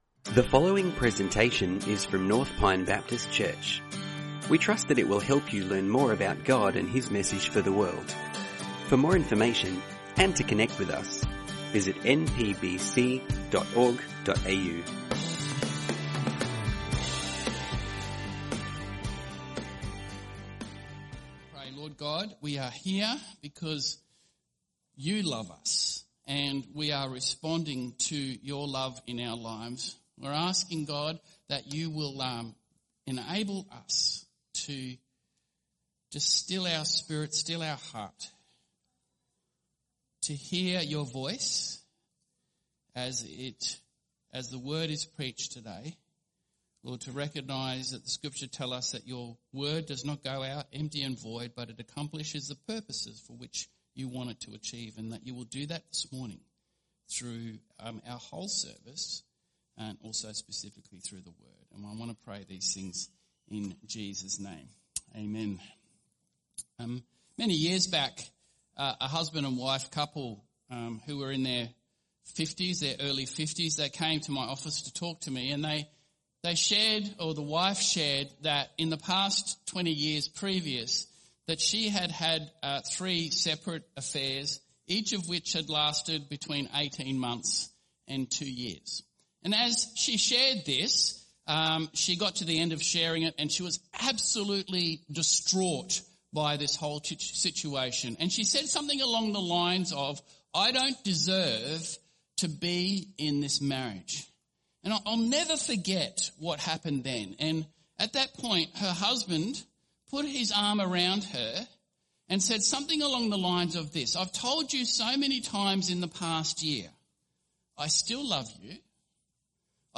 Sermons | North Pine Baptist Church